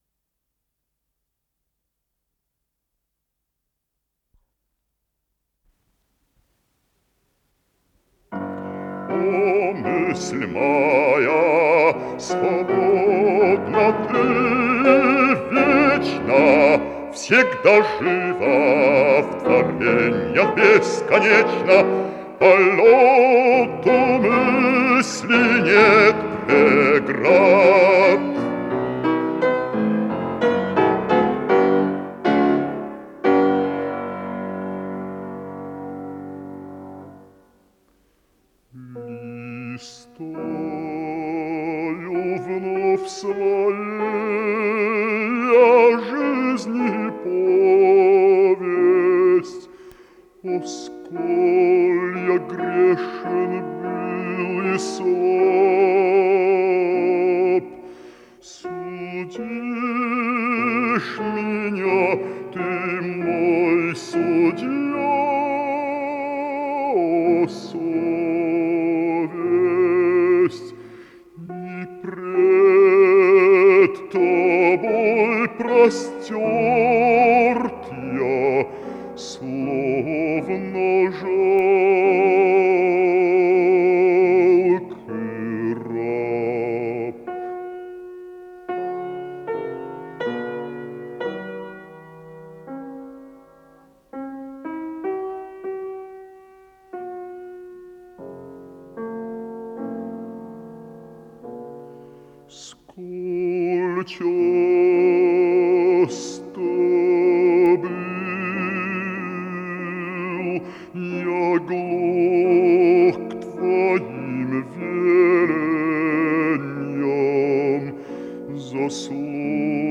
с профессиональной магнитной ленты
пение
фортепиано